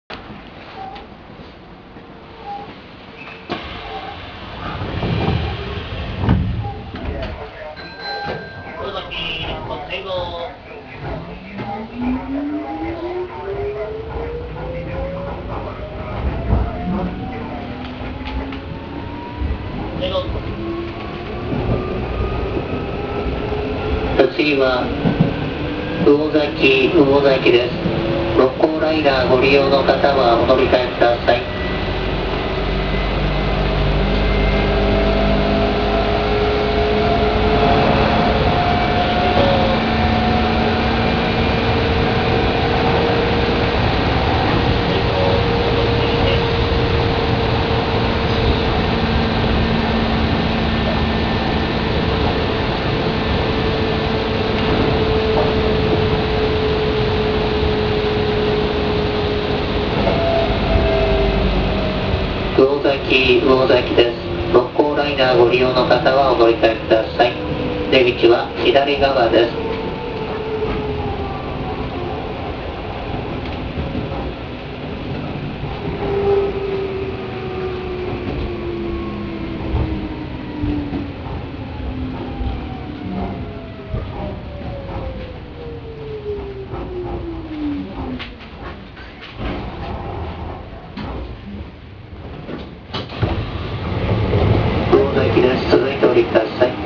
〜車両の音〜
・5000系走行音
【阪神本線】青木〜魚崎（1分36秒：527KB）
音自体は普通の抵抗制御ですが、加速がおかしいのがお分かり頂けるでしょうか。